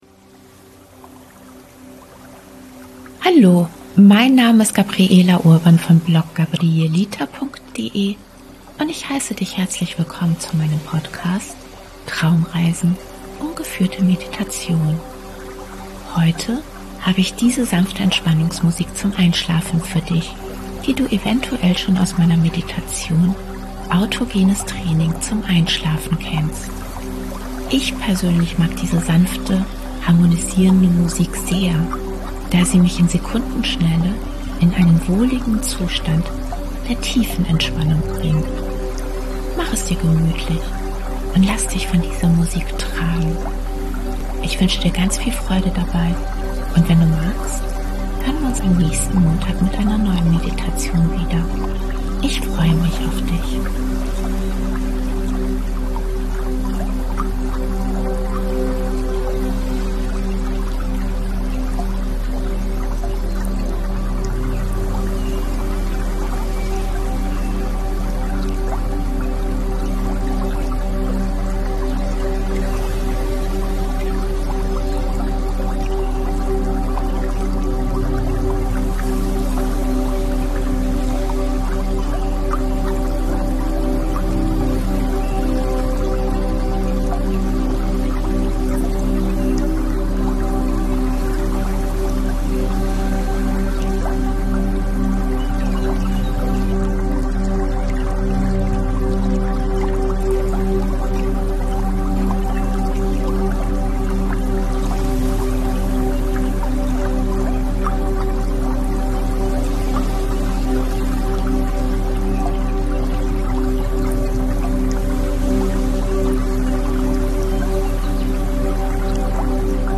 Ich persönlich mag diese sanfte, harmonisierende Einschlafmusik sehr, da sie mich in Sekundenschnelle in einen wohligen Zustand der Tiefenentspannung bringt.
Sanfte Entspannungsmusik zum Einschlafen 30 Minuten